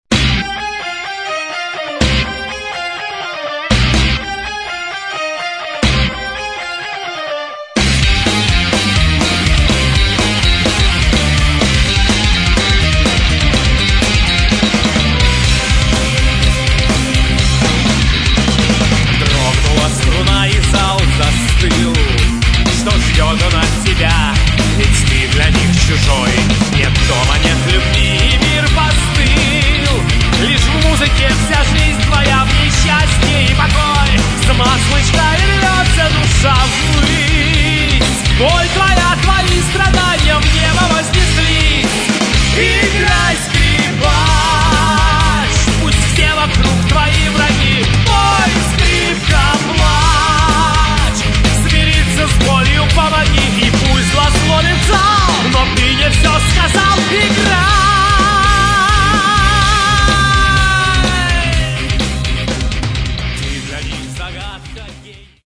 Metal
гитары
вокал, клавишные, флейта
бас
ударные